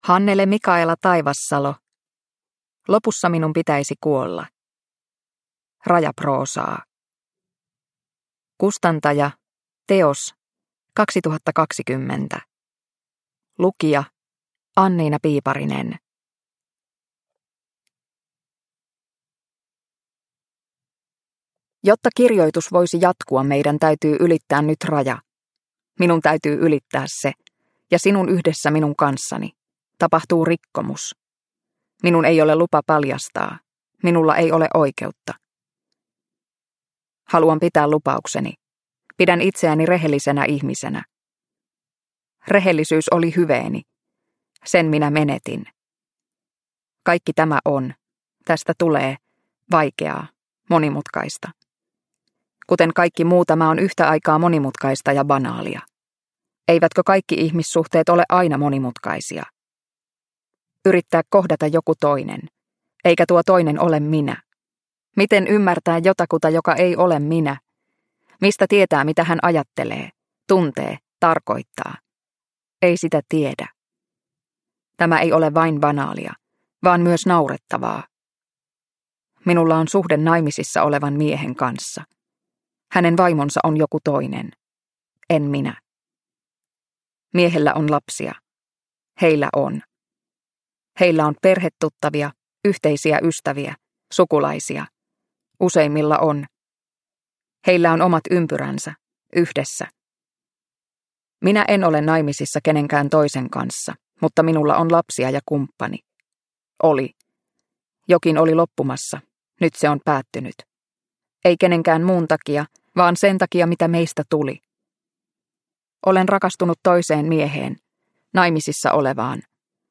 Lopussa minun pitäisi kuolla – Ljudbok – Laddas ner